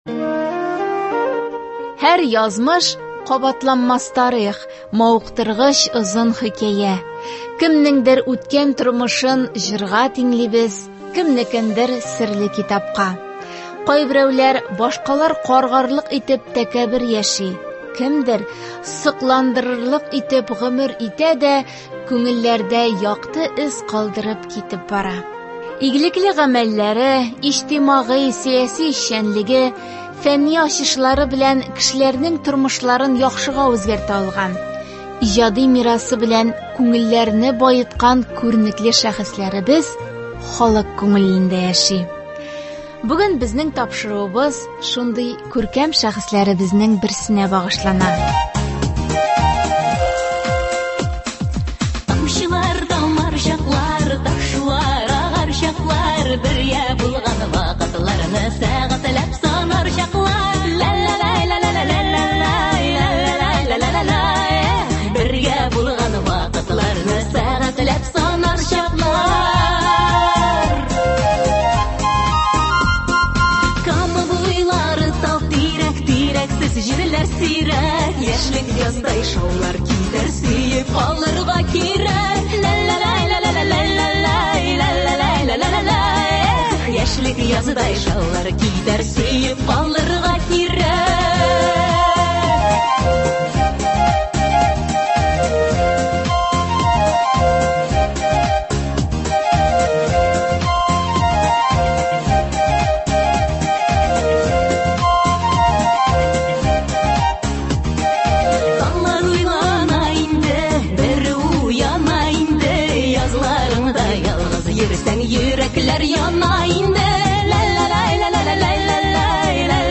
тыңлаучылар сорауларына җавап бирә